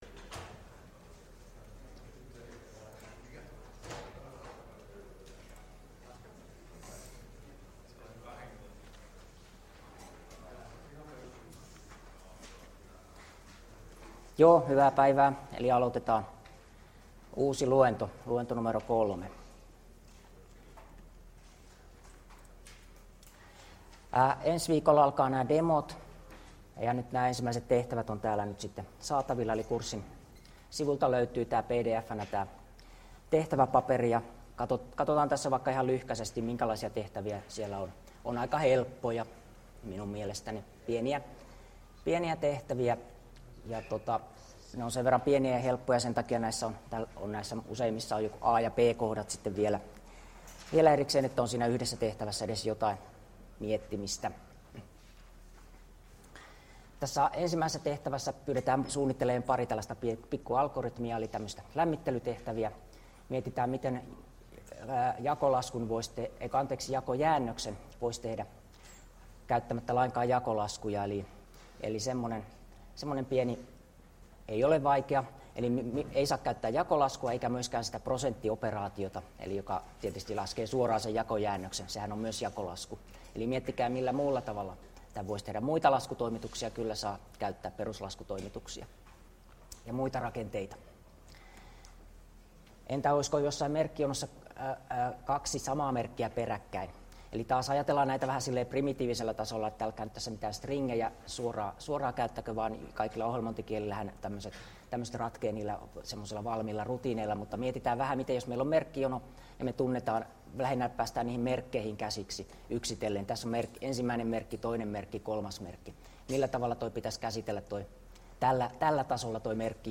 Luento 3 — Moniviestin